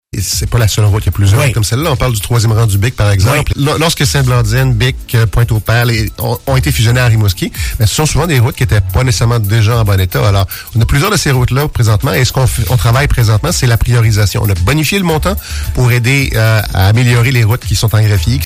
Le maire, Guy Caron, a expliqué que Rimouski est à la tâche pour revoir l’état de l’ensemble des routes rurales, les évaluer et réaliser un plan d’intervention.